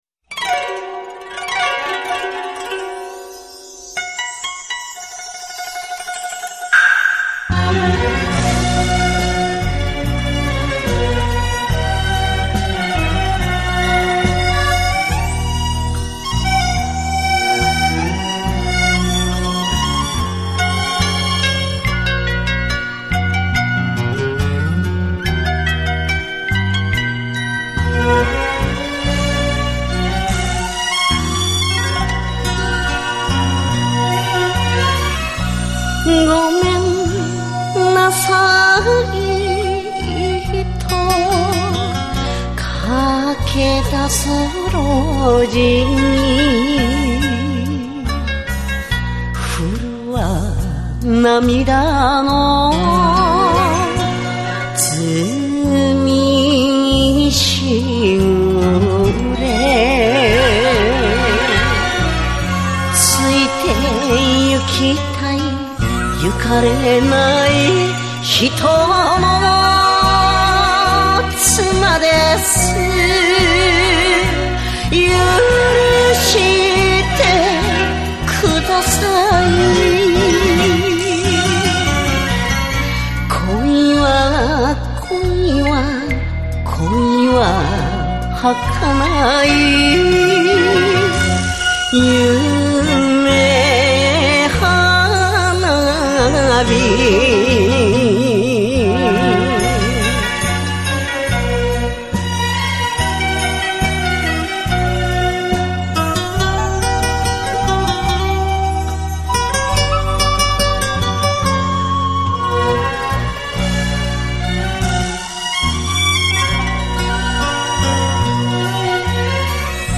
Жанр: enka